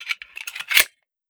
Foley / 45 ACP 1911 Pistol - Magazine Load 002.wav